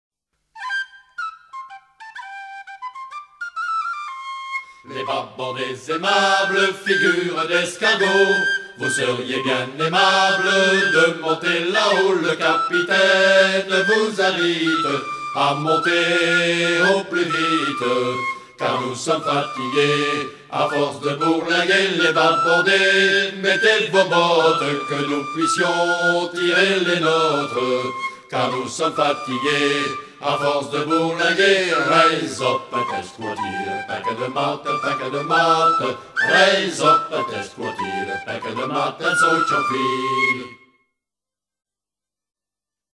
circonstance : maritimes
Genre strophique